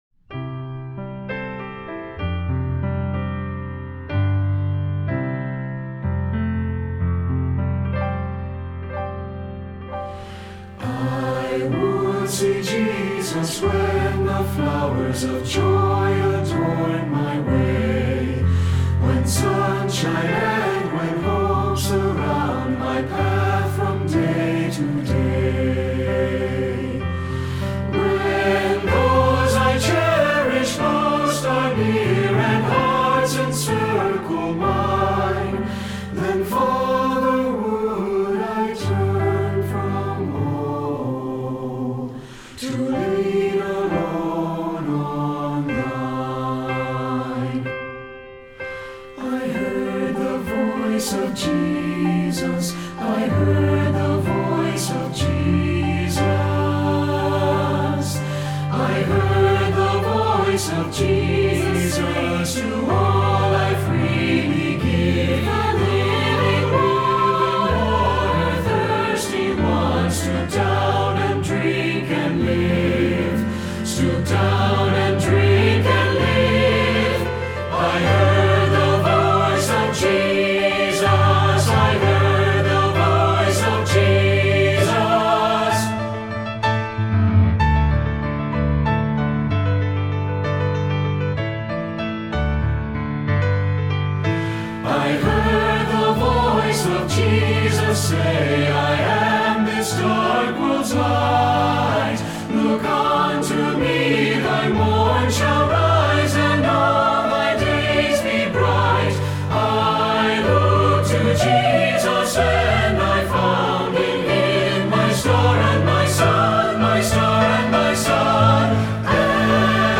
Feuillet pour Chant/vocal/choeur - SAB